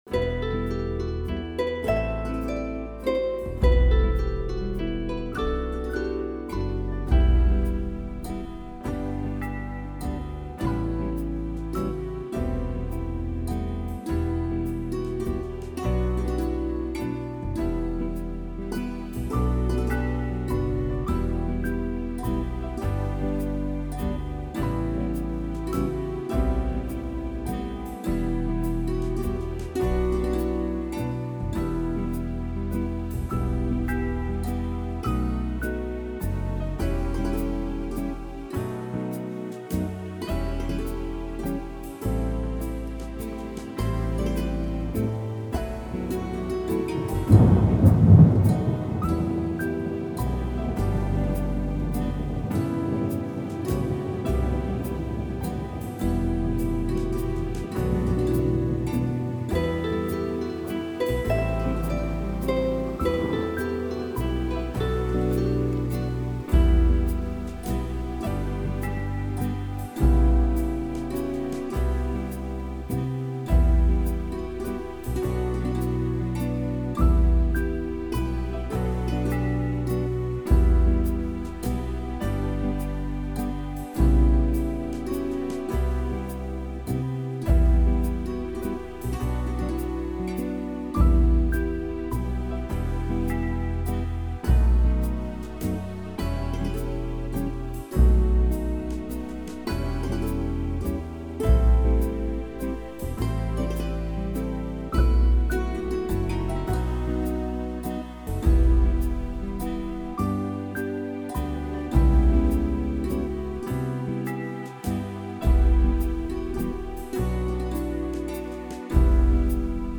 Song style: folk
Backing track